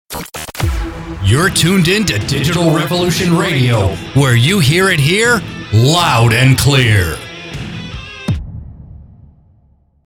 These short, produced pieces typically feature voice and effects and play between songs or segments to identify and position the station.